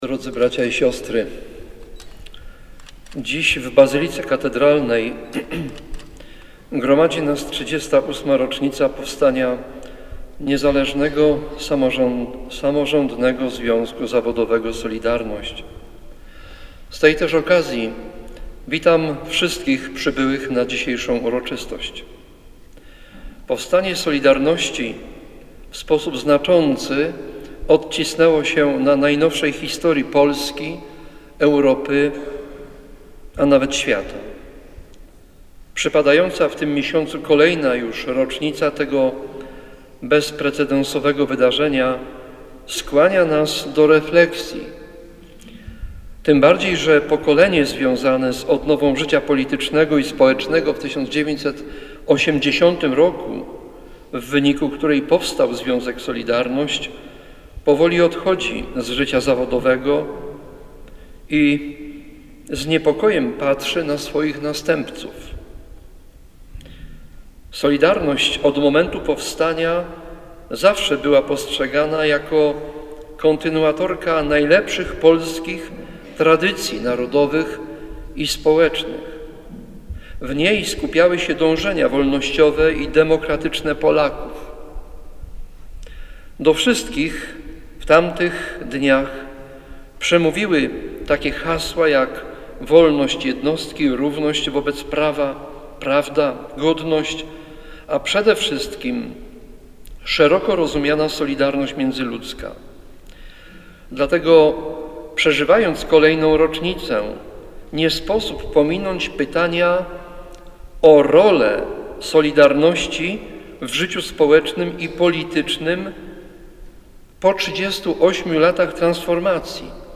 Następnie uczestnicy uroczystości udali się do Bazyliki Katedralnej, gdzie Najświętszą Ofiarę odprawił biskup Andrzej Kaleta. W swojej homilii zwrócił uwagę na istotę ruchu powstałego 38 lat temu.